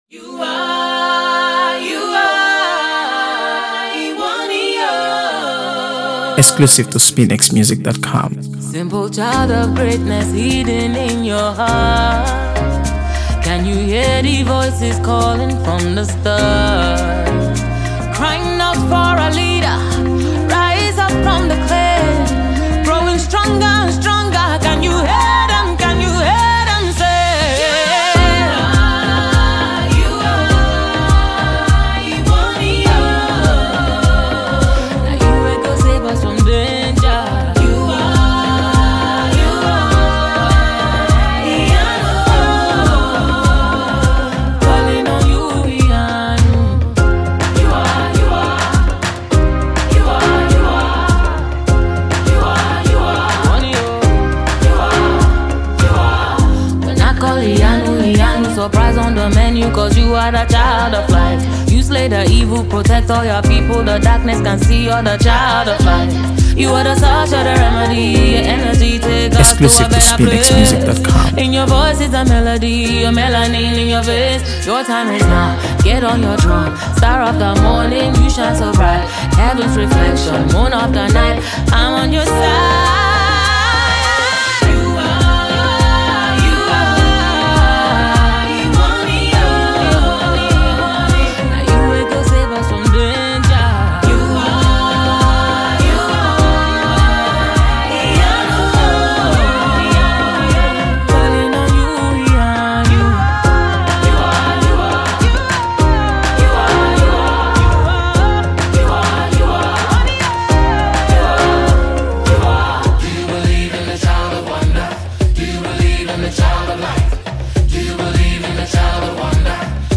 AfroBeats | AfroBeats songs
a fresh, uplifting message